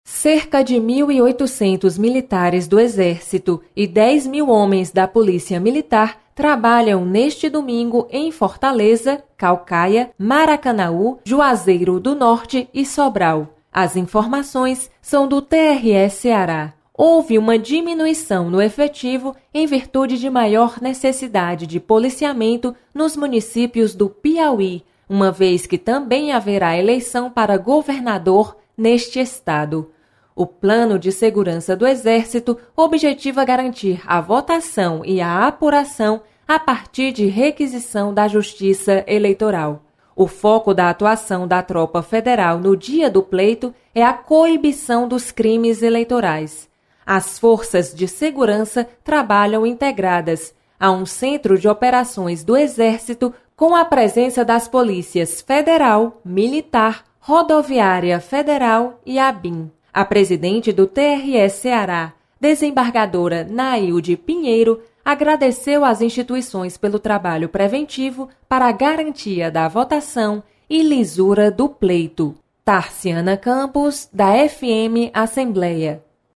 Você está aqui: Início Comunicação Rádio FM Assembleia Notícias Eleições 2018